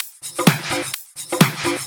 Index of /VEE/VEE Electro Loops 128 BPM
VEE Electro Loop 053.wav